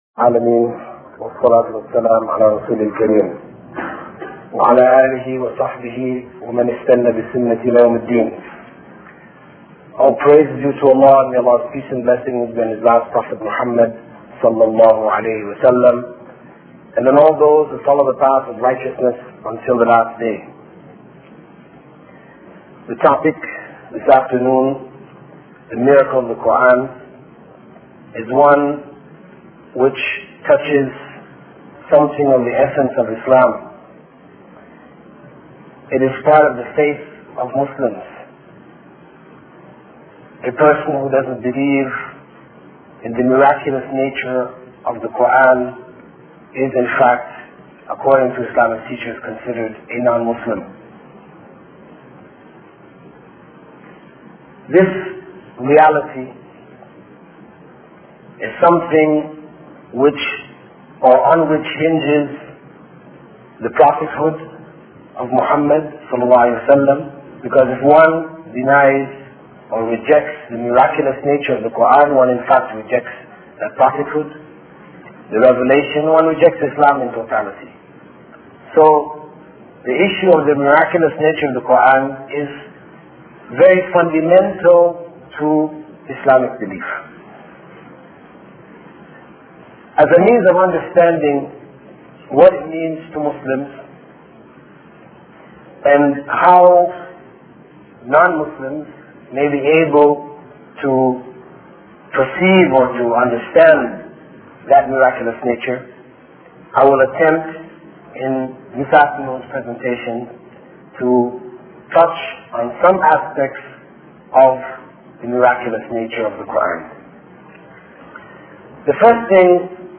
Lecture - Miracles Of Quran.mp3